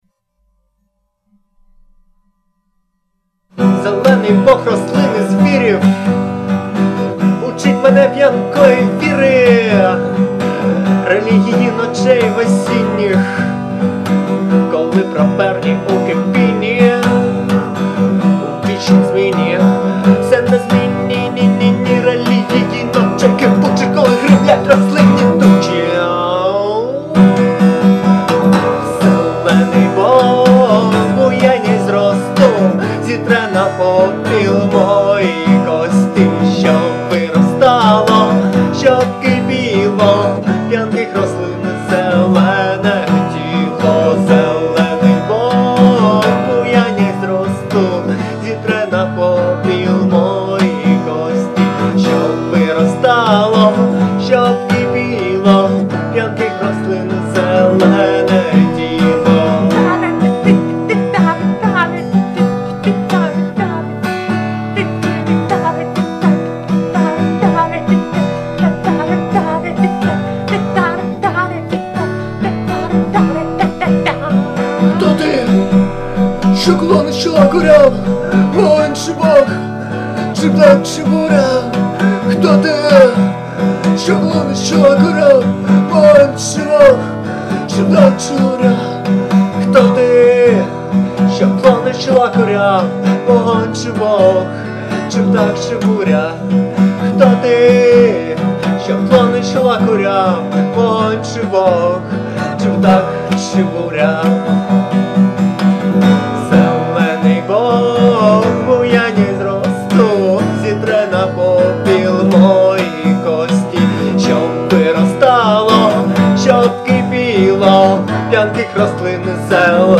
акустика